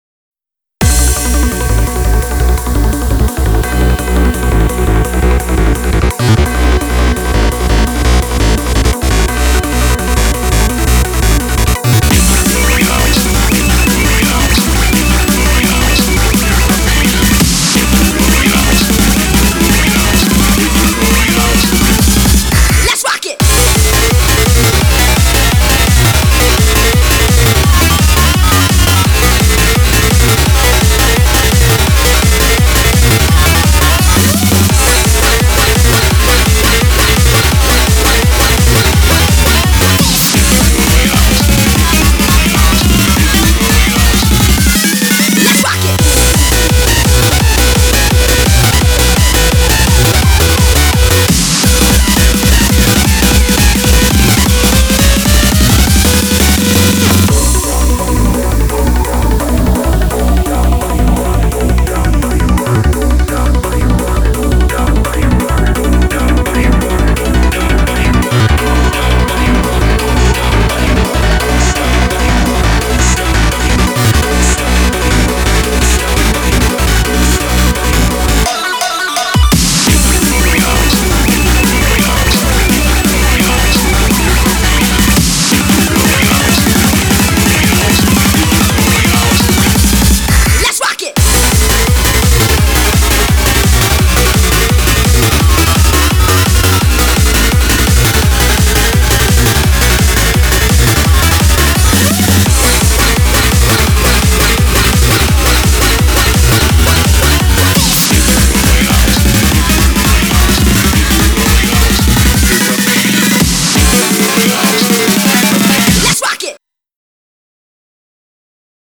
BPM170
Audio QualityPerfect (High Quality)